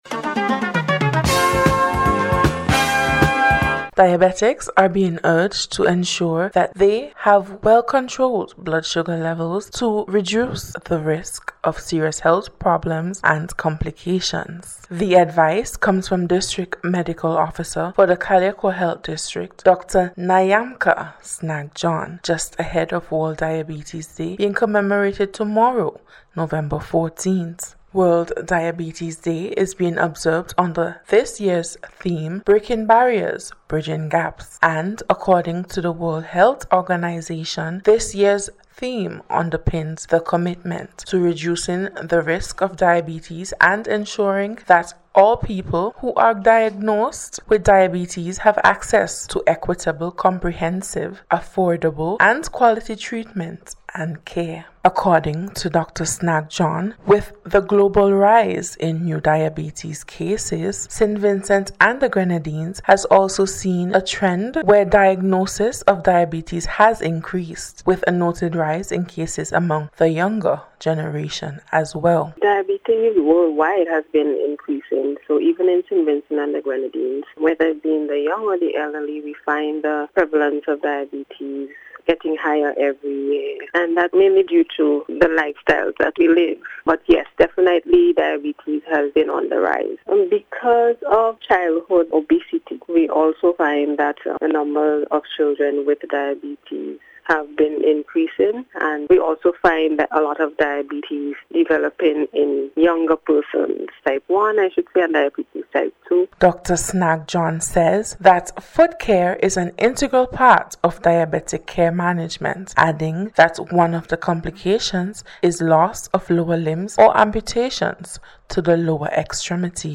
NBC’s Special Report- Wednesday 13th November,2024
DIABETES-FOOTCARE-REPORT.mp3